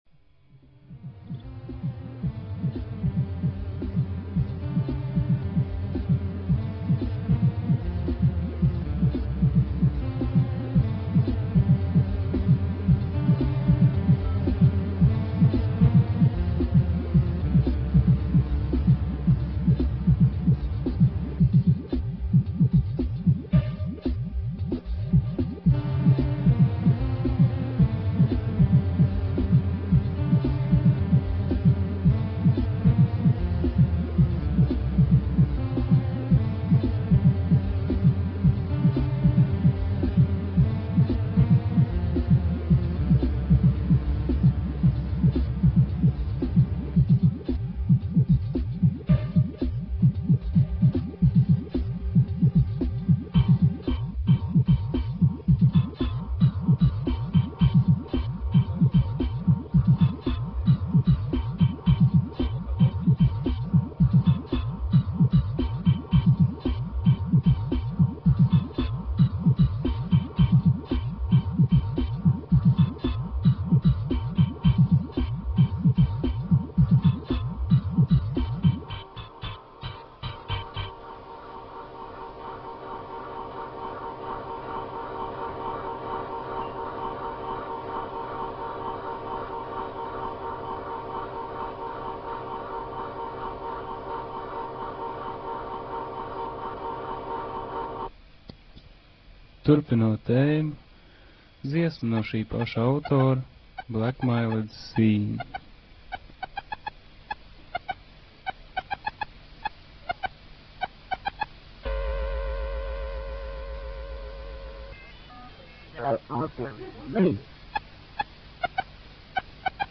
Ozone live session